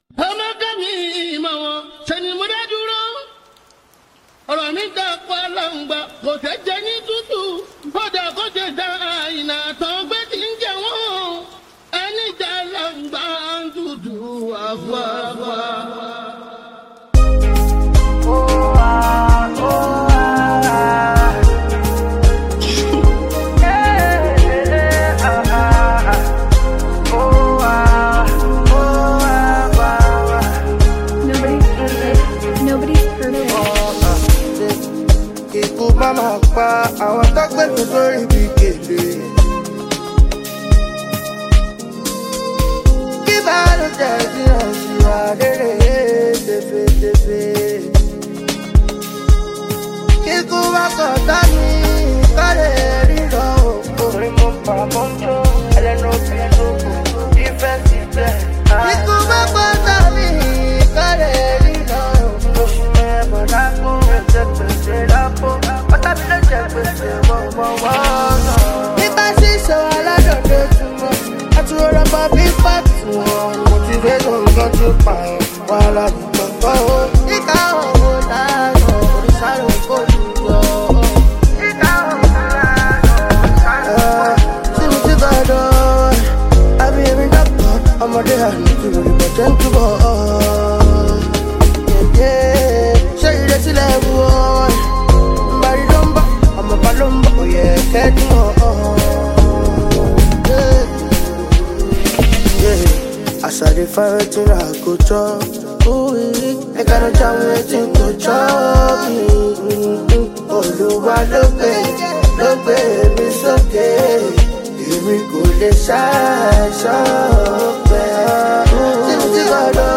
Afrobeats
Afrobeat with contemporary influences